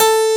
Index of /90_sSampleCDs/Roland - Rhythm Section/KEY_Pop Pianos 4/KEY_Pop Pno + EP